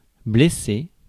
Ääntäminen
US : IPA : [ˈɪn.dʒɚ]